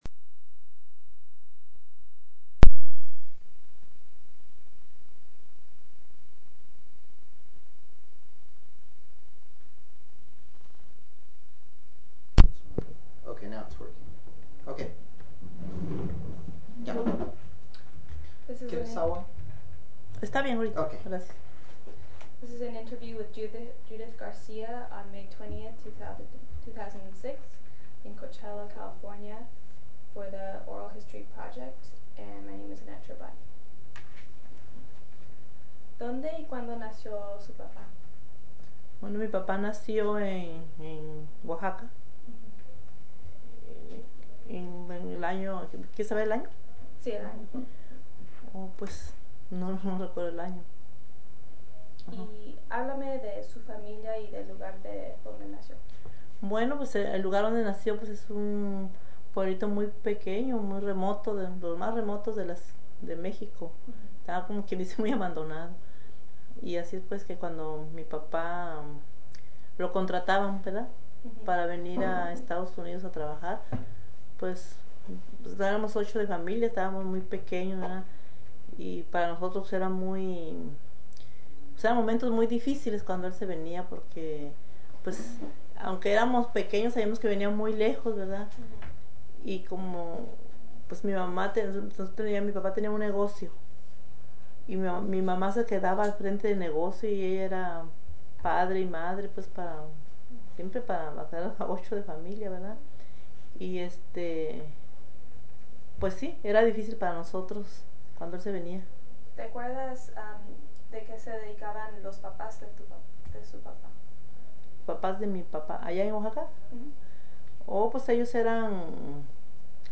Original Format Mini Disc
Location Coachella, CA